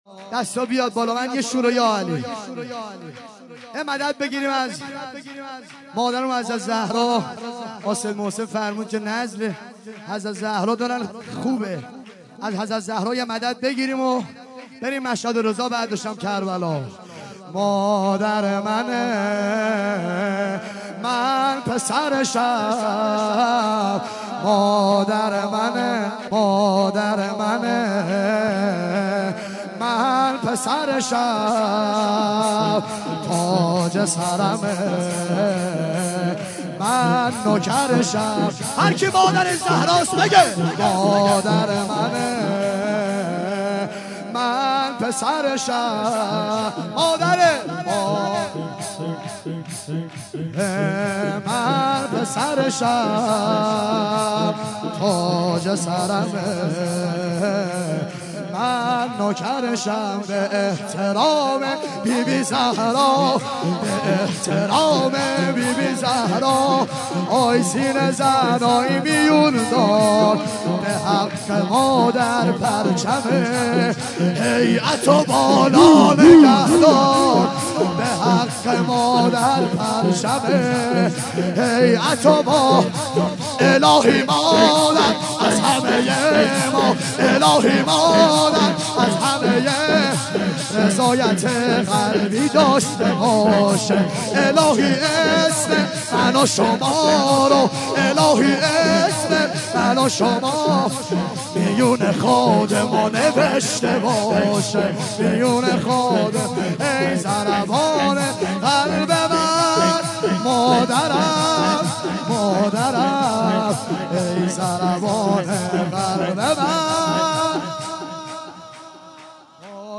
7- مادر منه من پسرشم - شور